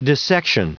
Prononciation du mot dissection en anglais (fichier audio)
Prononciation du mot : dissection